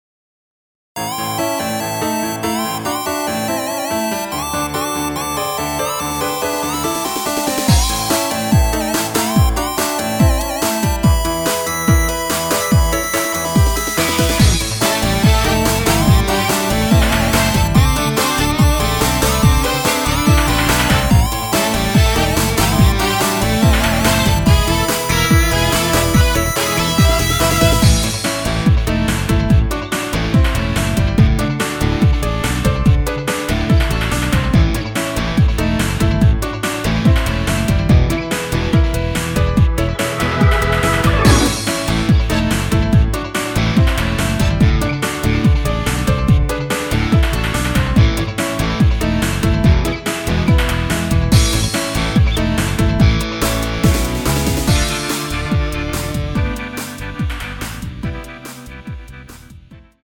(-2) 내린 MR 입니다.(미리듣기 참조)
Bb
앞부분30초, 뒷부분30초씩 편집해서 올려 드리고 있습니다.